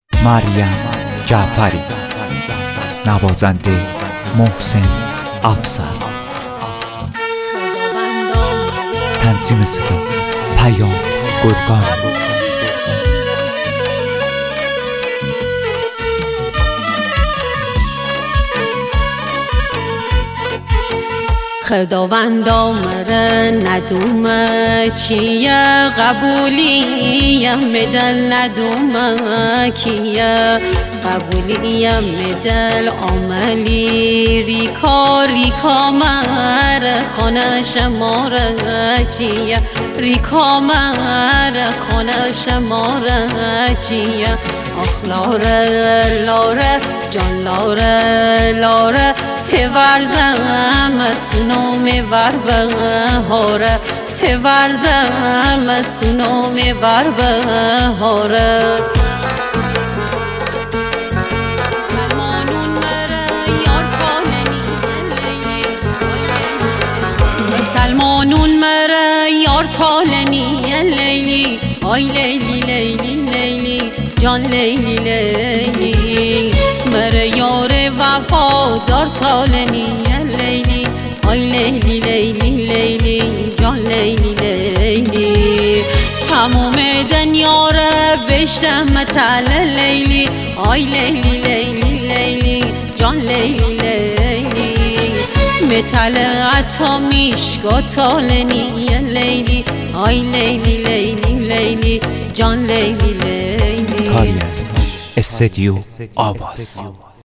اصیل خوانی